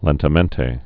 (lĕntə-mĕntā)